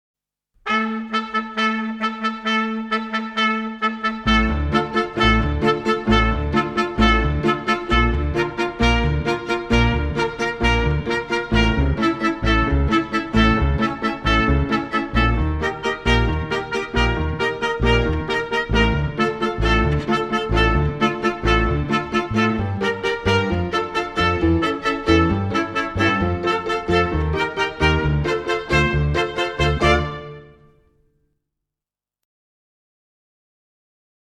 Högtidlig musikinsats